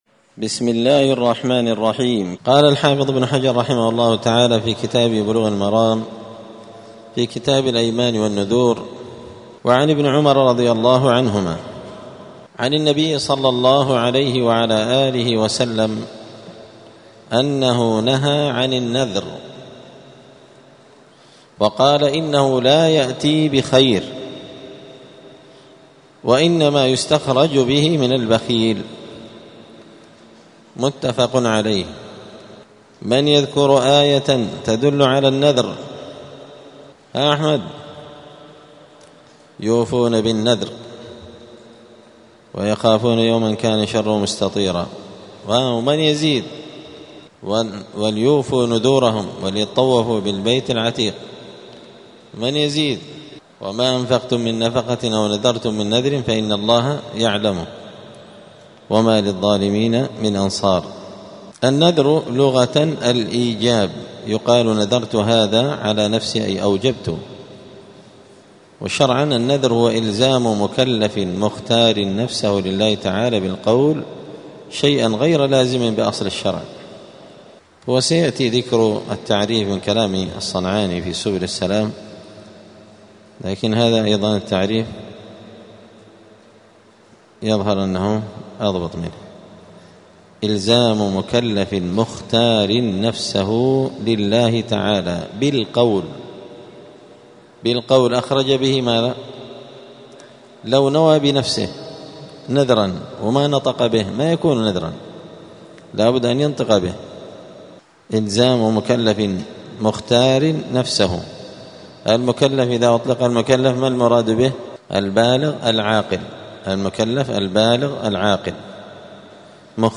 *الدرس الثاني عشر (12) {حكم النذر}*
دار الحديث السلفية بمسجد الفرقان قشن المهرة اليمن